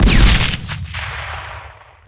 smallcannon.wav